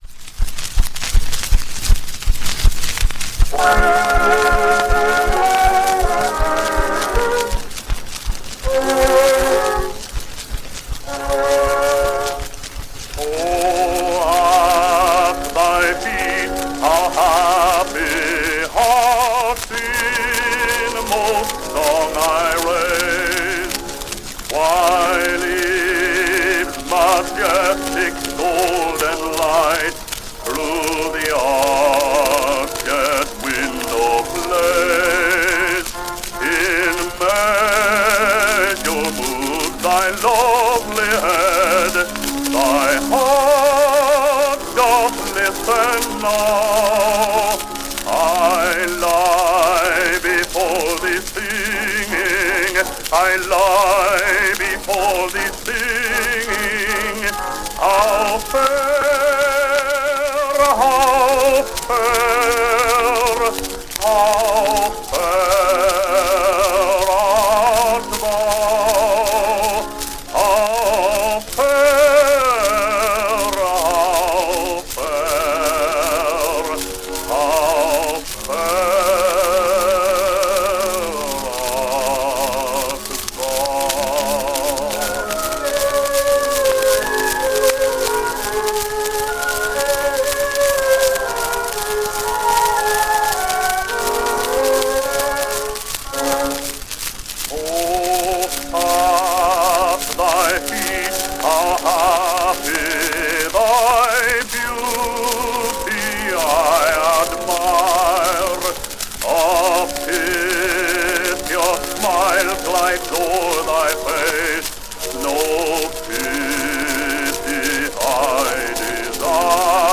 Bass-baritone solo with orchestra accompaniment.
Popular music—1911-1920.